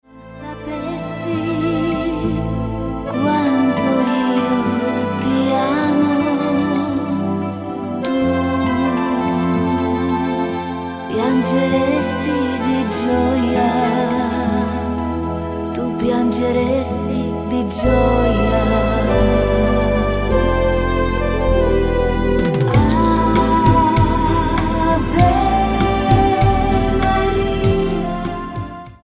STYLE: Latin